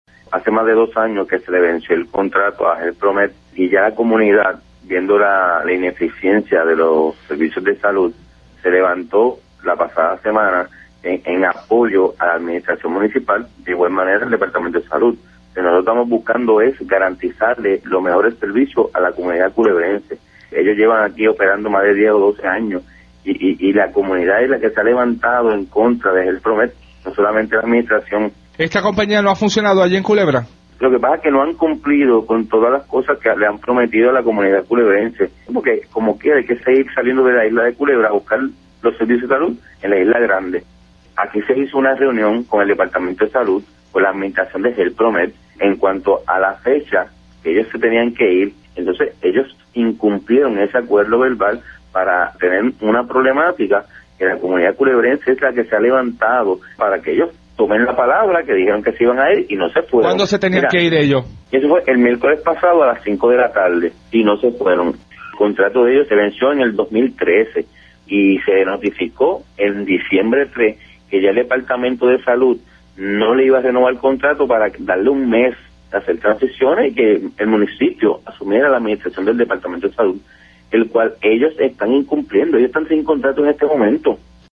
En declaraciones para la emisora colega Radio Isla 1320, el mandatario sostuvo que la compañía HealthPromed, que administra el CDT desde hace más de 10 años, no ha cumplido con los acuerdos establecidos con la comunidad.